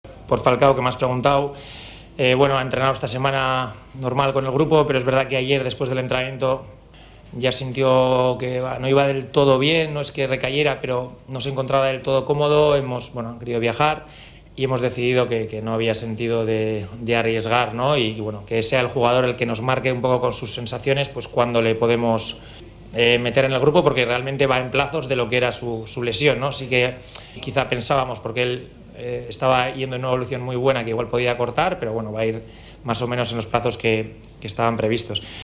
(Andoni Iraola, DT deL Rayo Vallecano en rueda de prensa)